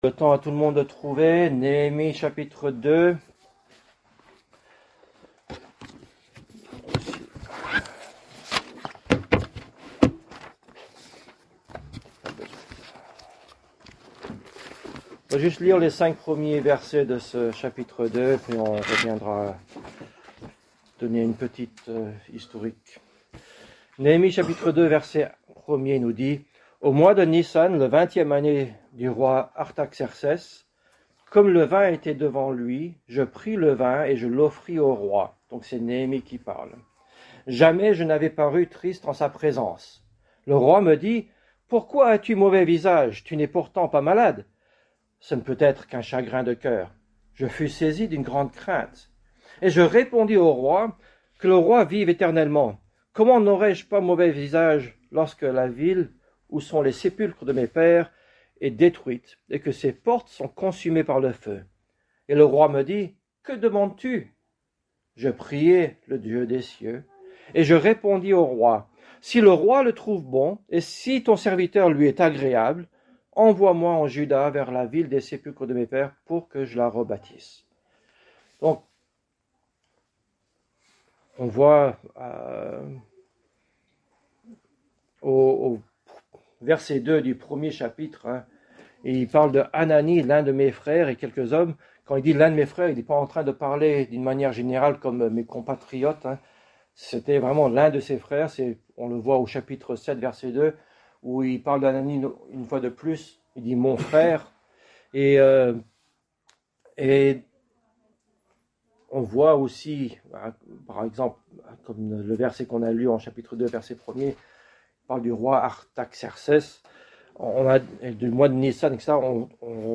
Thème: Confiance , Prière Genre: Prédication